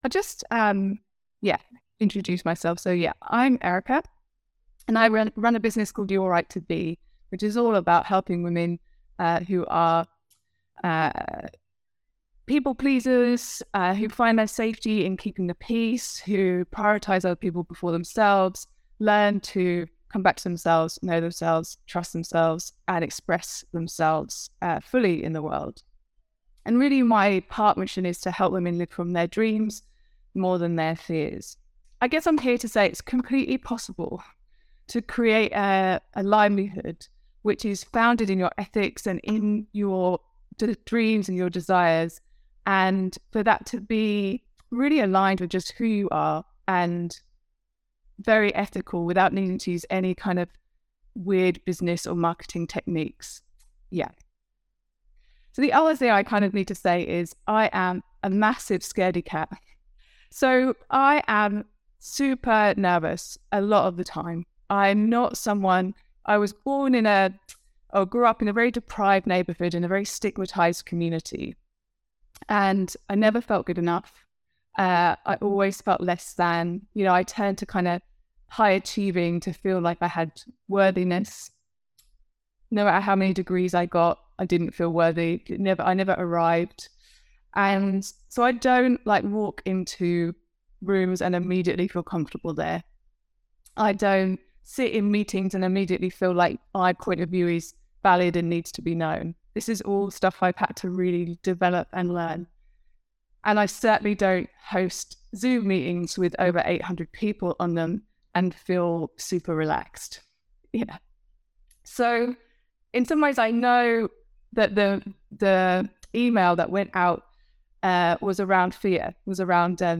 Welcome to the Masterclass!